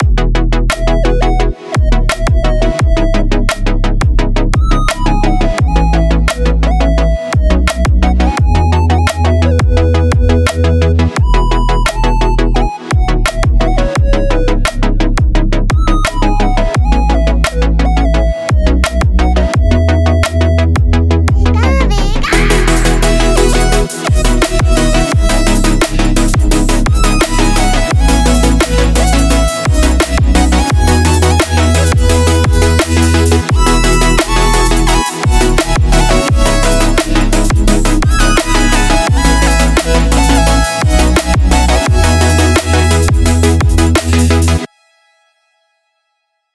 happy cute little music